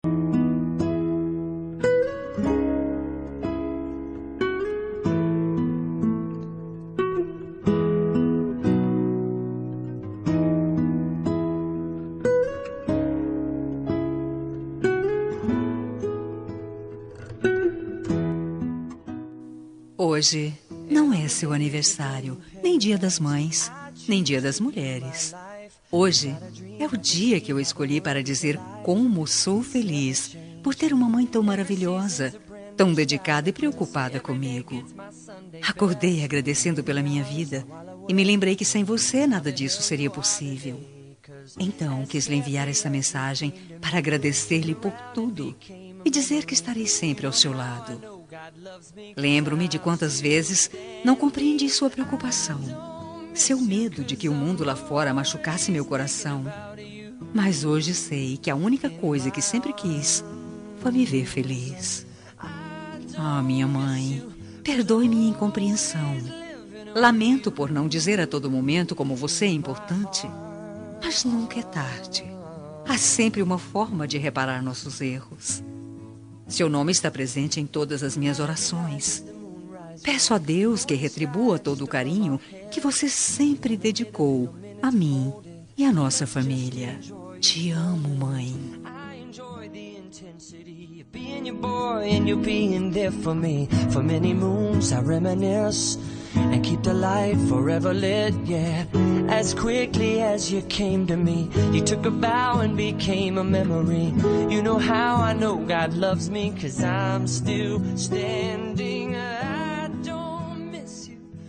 Homenagem para Mãe – Voz Feminina – Cód: 8141